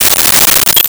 Coins Thrown 02
Coins Thrown 02.wav